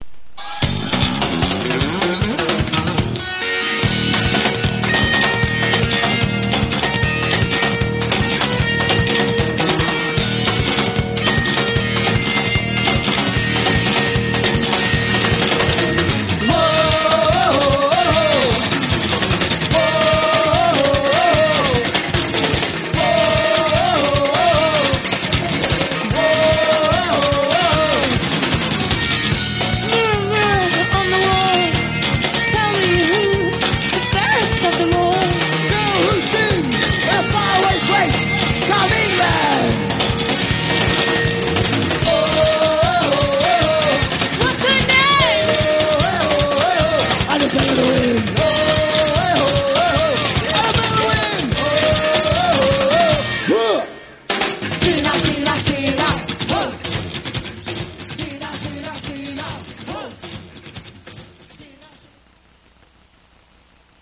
260Kb Live (Edit)